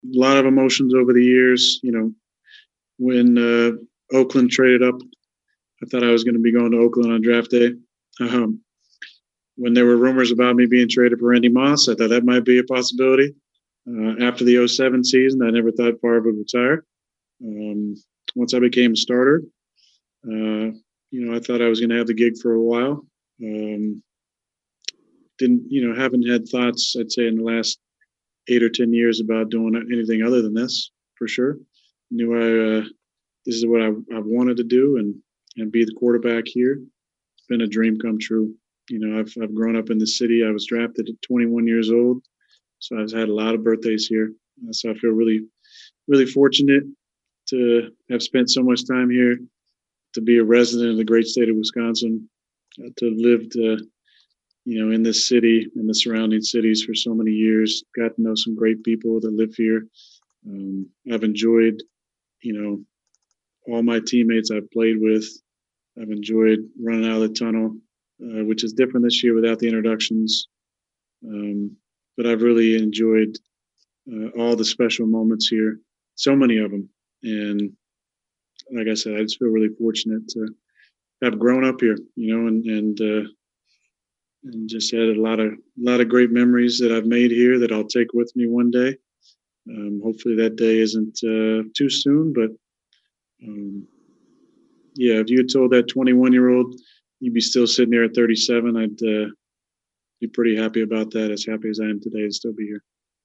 Rodgers covered all the usual topics at his weekly zoom session, the addition of Austin, a stout Philadelphia Eagles defense and the fact it’s another candle blowing day for him. He was asked if he ever thought about first arriving in Green Bay and pondering what he’s become lo these many birthdays later?